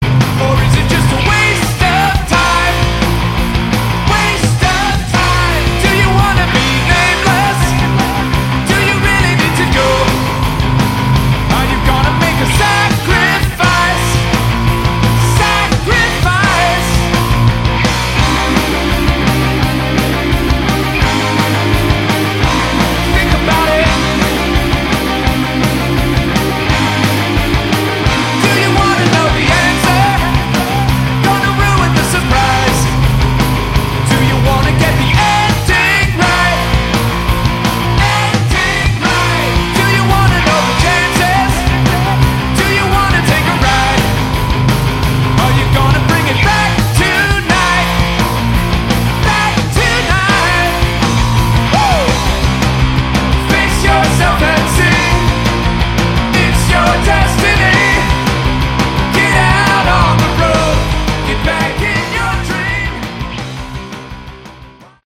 Category: Hard Rock
vocals, all instruments
Bass
Drums
Guitars